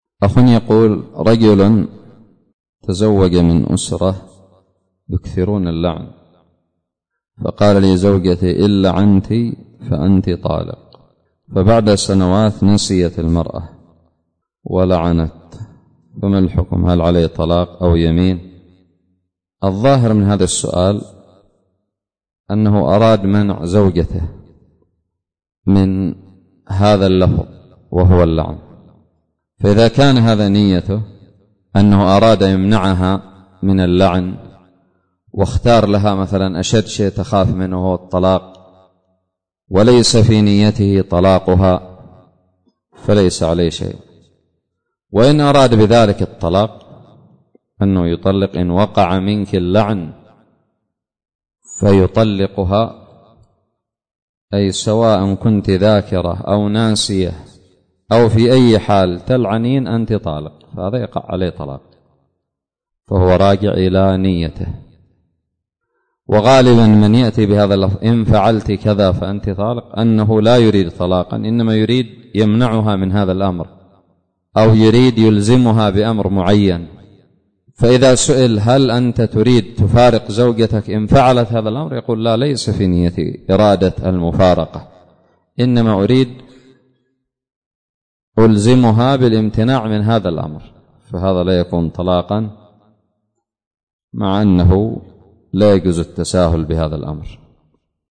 سؤال قدم لفضيلة الشيخ حفظه الله